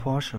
Dr. Ing. h.c. F. Porsche AG, usually shortened to Porsche (German pronunciation: [ˈpɔʁʃə]
De-Porsche.ogg.mp3